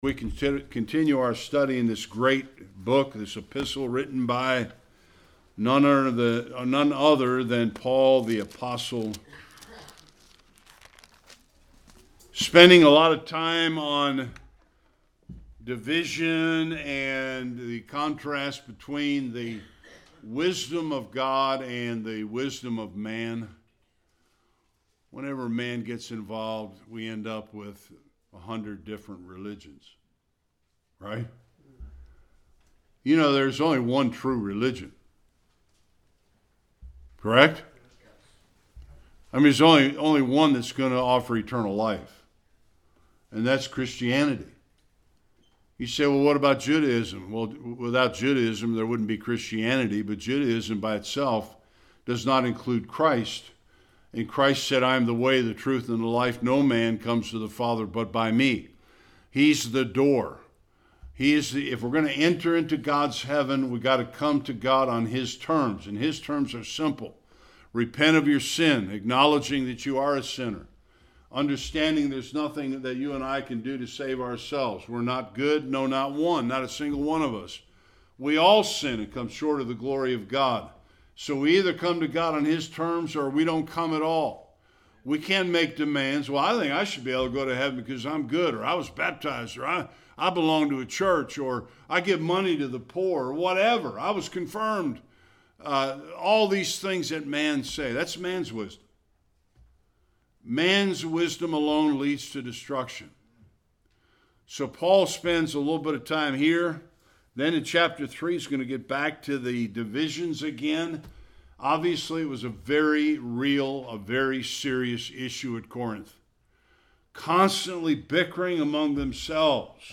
1-9 Service Type: Sunday Worship 2 of the 3 basic fundamentals of the Gospel message.